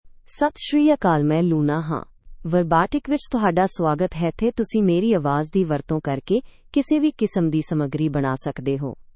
Luna — Female Punjabi (India) AI Voice | TTS, Voice Cloning & Video | Verbatik AI
Luna is a female AI voice for Punjabi (India).
Voice sample
Listen to Luna's female Punjabi voice.
Female
Luna delivers clear pronunciation with authentic India Punjabi intonation, making your content sound professionally produced.